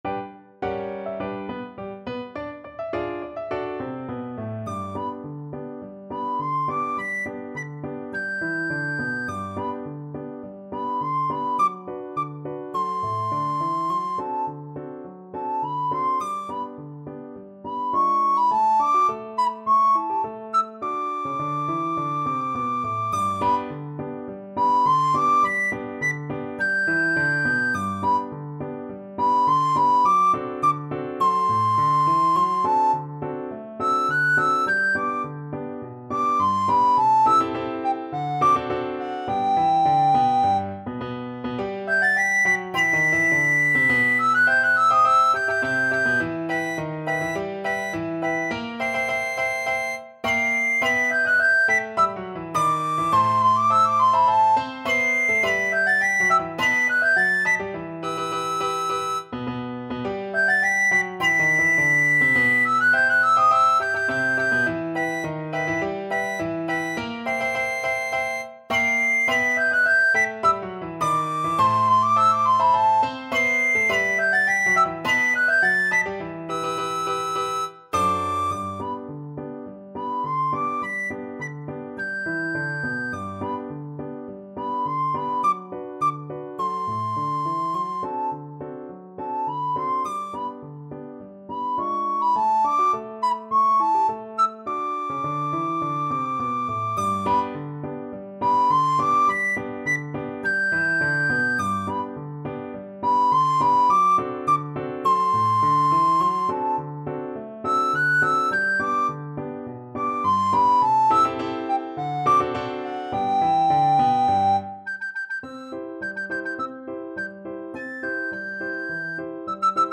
Free Sheet music for Soprano (Descant) Recorder
Quick March = c.104